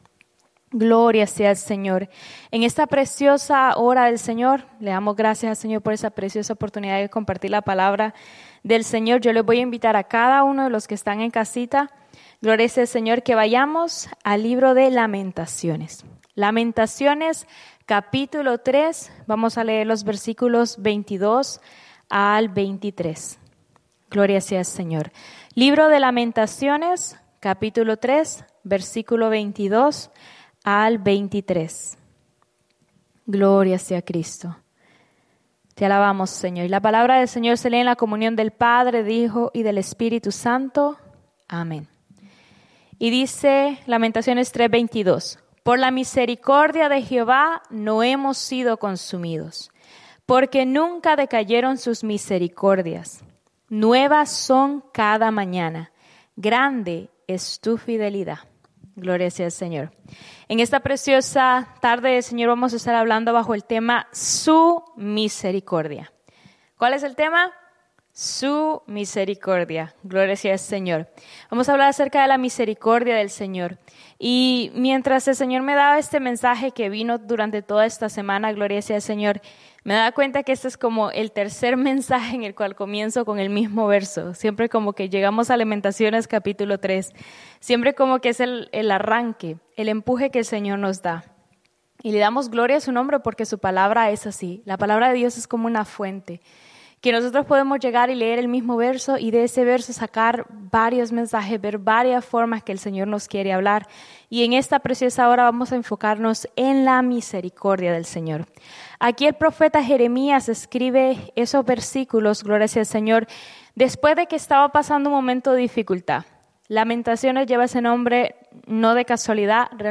Predica
Souderton,PA